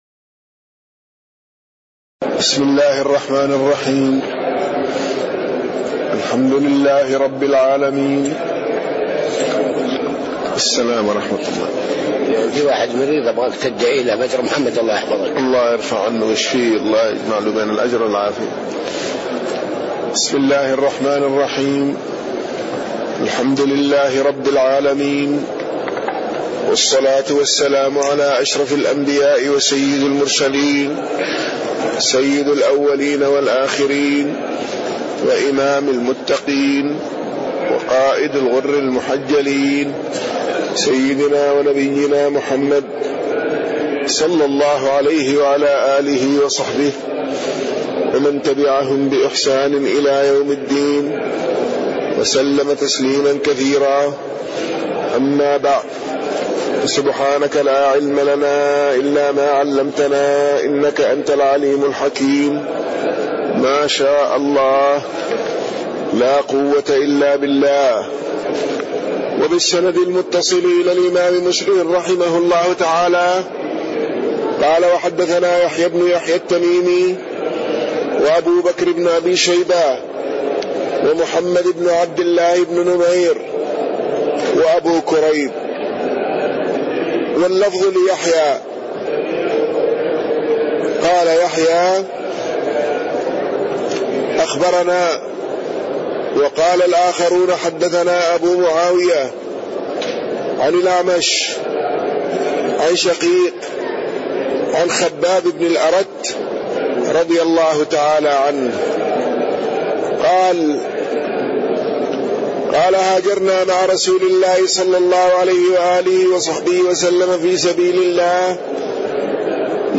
تاريخ النشر ١٢ ربيع الأول ١٤٣٢ هـ المكان: المسجد النبوي الشيخ